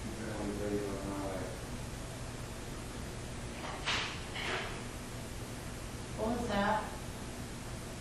In the room where I was sitting something fell. To me it sounded like change/money being dropped from the celling, but the audio doesn’t capture it that way.
Drop.wav